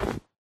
snow1.ogg